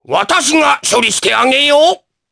Oddy-Vox_Skill4_jp.wav